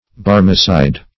Barmecide \Bar"me*cide\, n.
barmecide.mp3